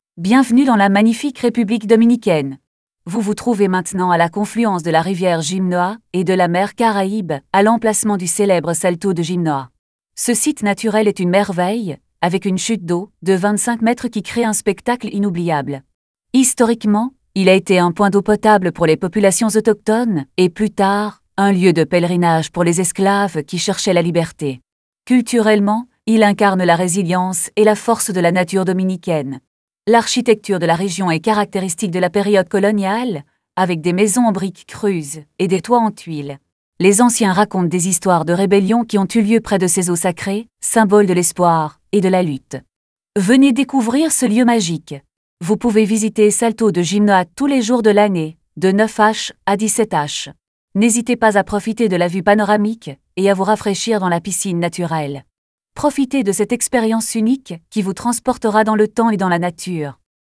tts